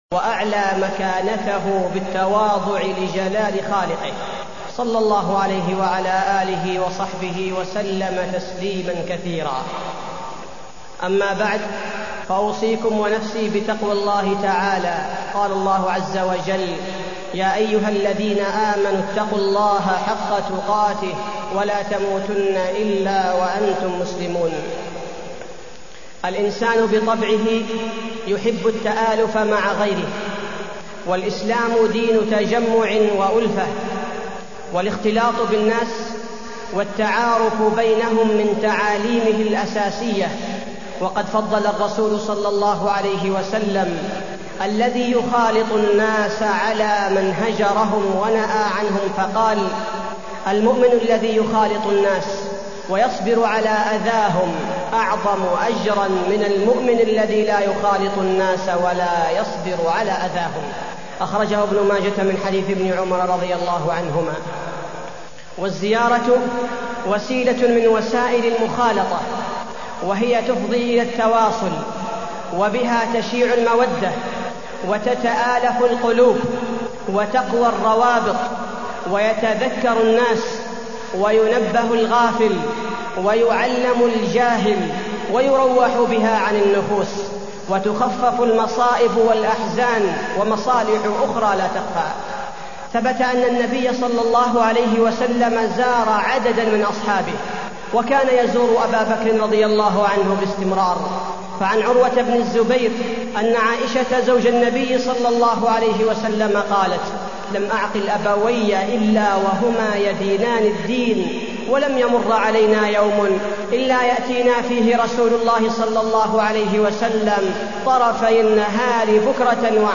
تاريخ النشر ٦ جمادى الأولى ١٤٢٢ هـ المكان: المسجد النبوي الشيخ: فضيلة الشيخ عبدالباري الثبيتي فضيلة الشيخ عبدالباري الثبيتي آداب الزيارة